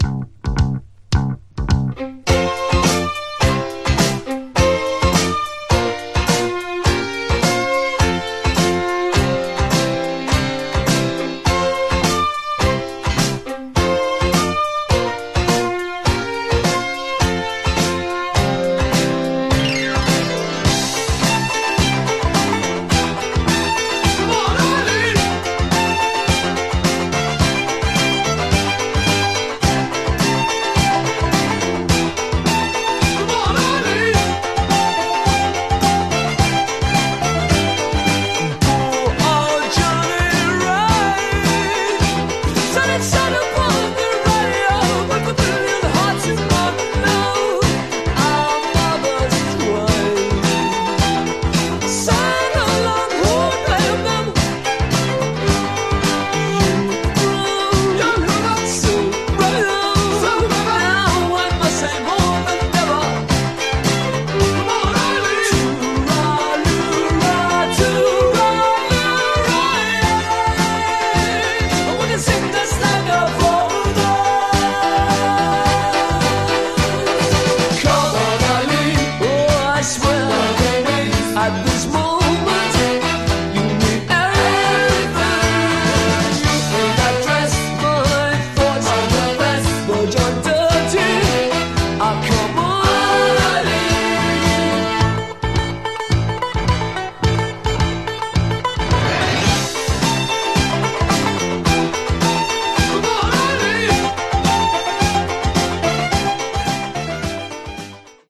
Contains an edited version of the LP track.